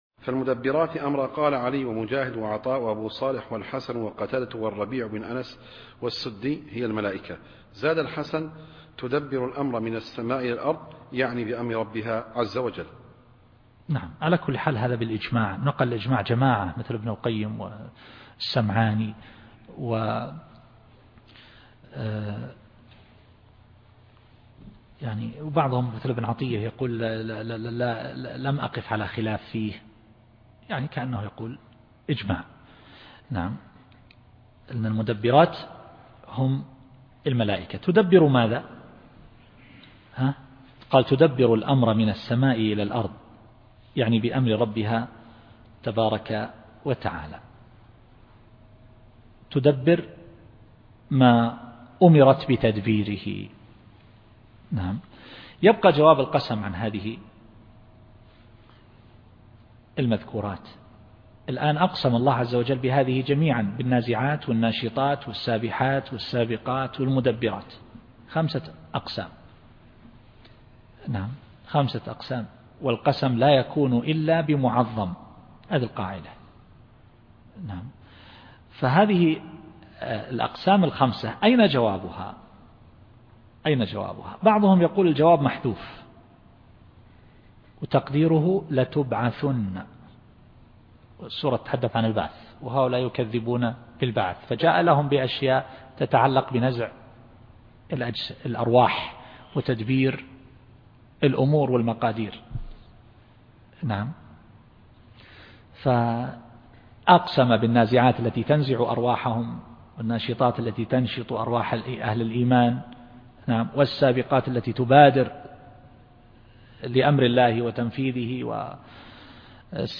التفسير الصوتي [النازعات / 5]